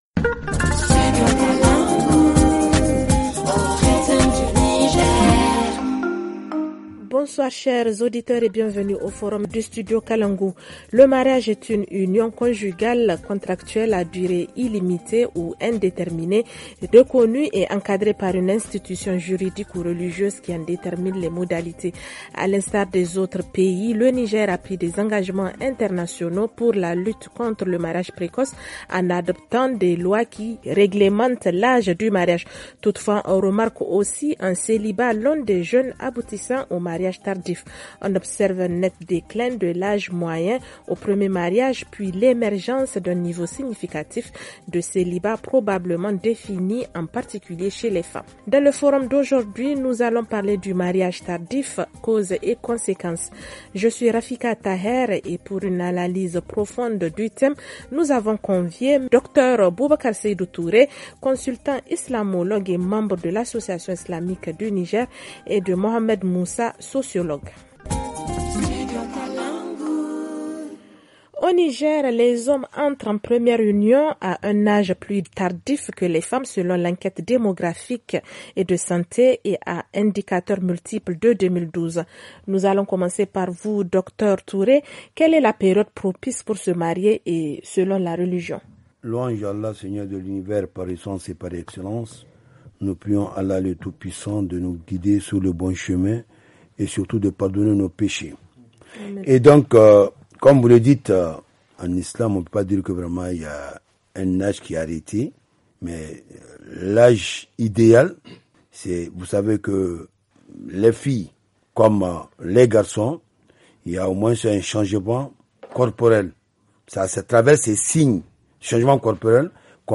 FR Le forum en français Télécharger le forum ici.
ok-FR-FORUM-MARIAGE-TARDIF-DES-JEUNES-NIGER-2108.mp3